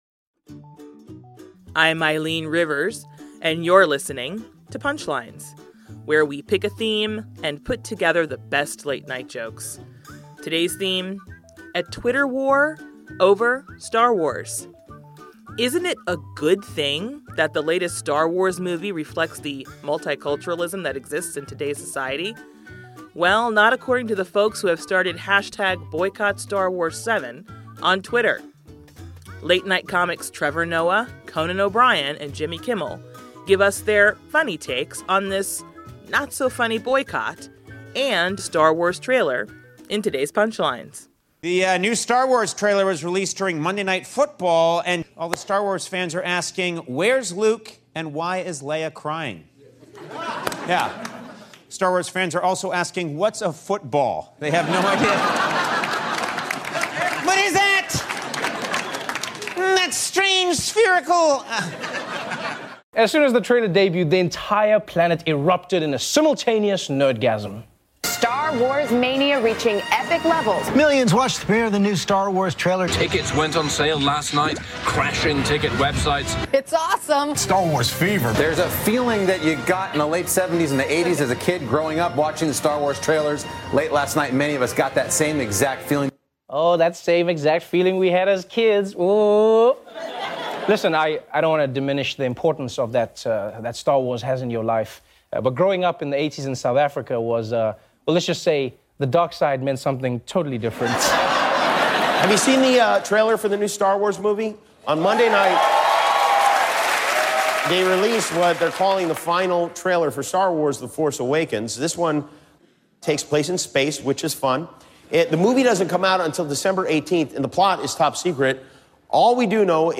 The late-night comics on the anger from some fans about the latest "Star Wars" trailer.